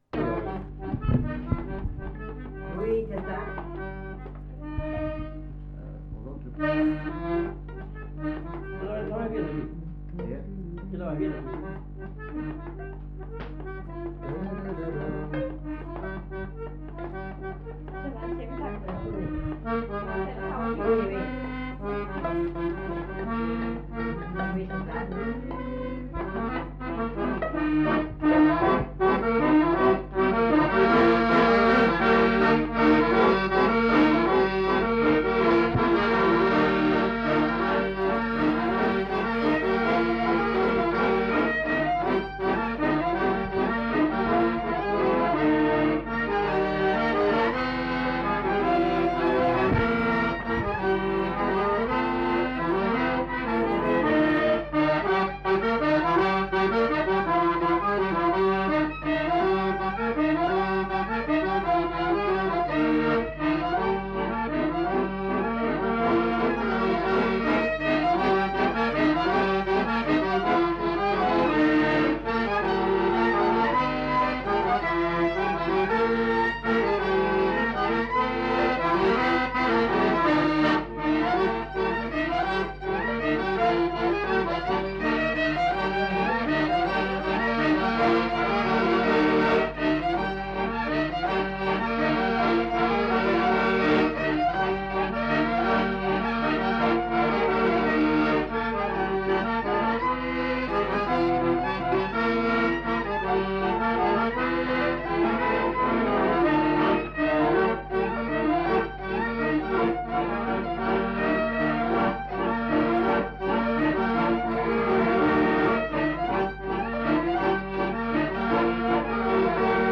Répertoire de danses des Petites-Landes interprété au violon et à l'accordéon chromatique